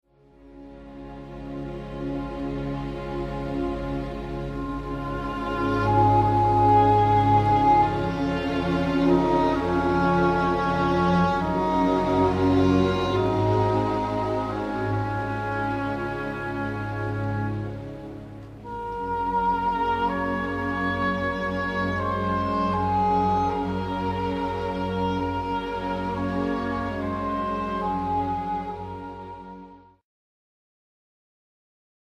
Música del Romántico V